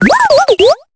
Cri de Croquine dans Pokémon Épée et Bouclier.